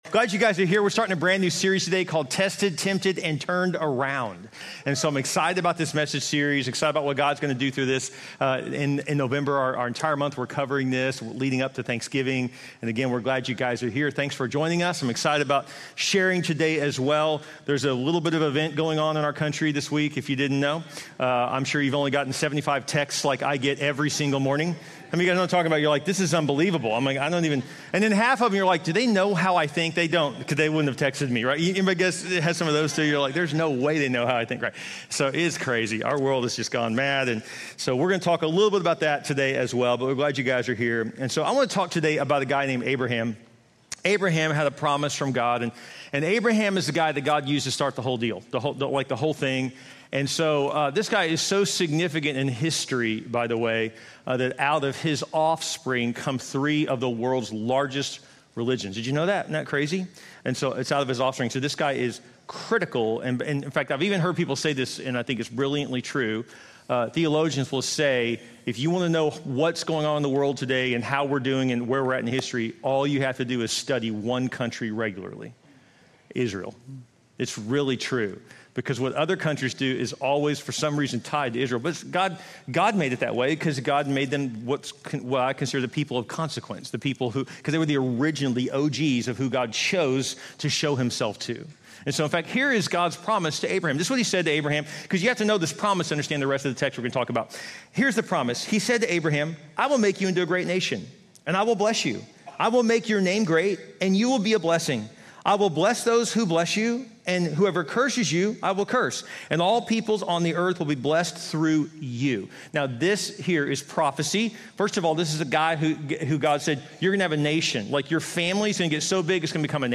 1 When Prayers Feel Unanswered 41:57 Play Pause 3d ago 41:57 Play Pause Play later Play later Lists Like Liked 41:57 This sermon dives into the journey of faith in times when it seems like God is silent or distant, using the Israelites’ crossing of the Red Sea as a powerful example. Through four reflective questions, it challenges listeners to examine their obedience, attitude, patience, and trust.